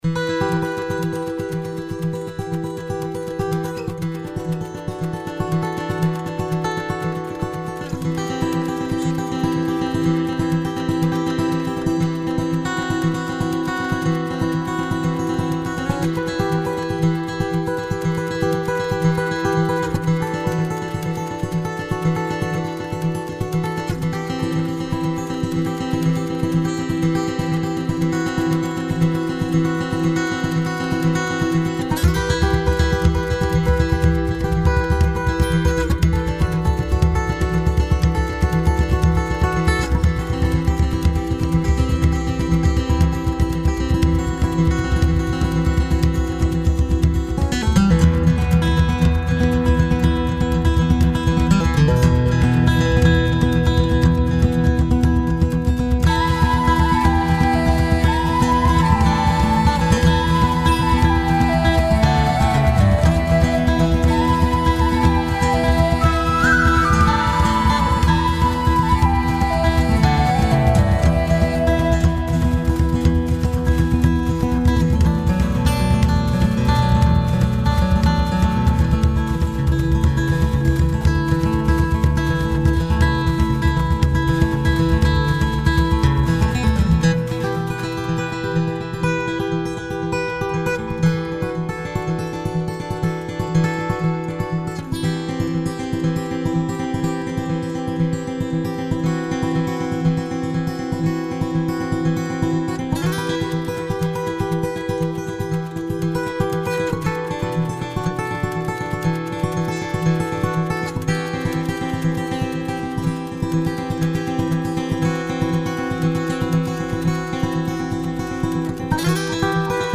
Es geht um ein irisch Folk anmutendes Stück.
Gitarre, Flöte und Trommel bisher.